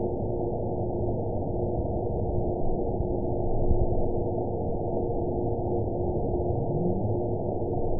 event 919839 date 01/26/24 time 00:58:22 GMT (1 year, 3 months ago) score 8.61 location TSS-AB03 detected by nrw target species NRW annotations +NRW Spectrogram: Frequency (kHz) vs. Time (s) audio not available .wav